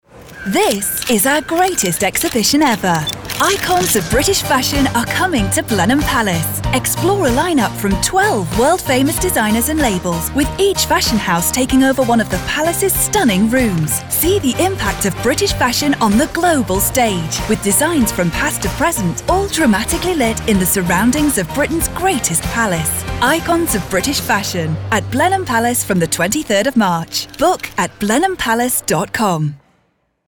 Luxury, Confident, Inspiring Voice Over Artists | Voice Fairy